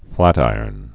(flătīərn)